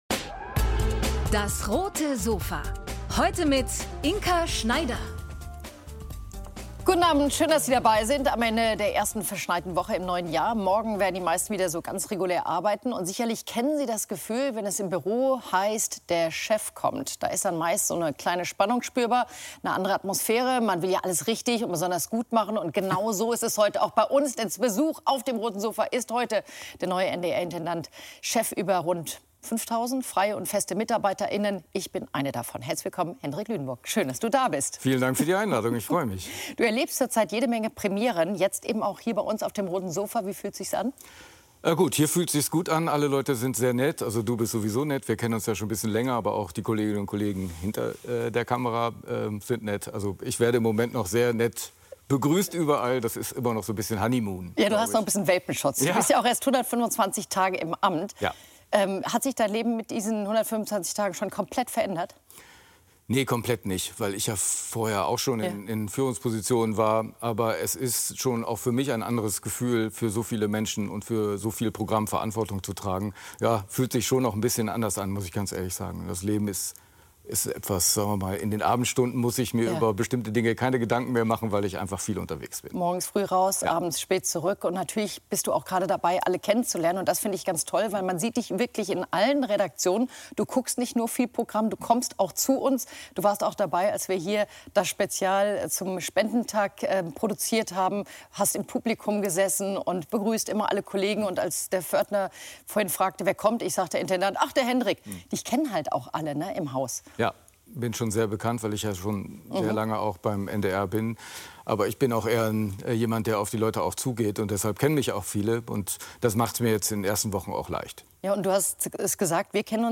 NDR Intendant Hendrik Lünenborg auf dem Roten Sofa ~ DAS! - täglich ein Interview Podcast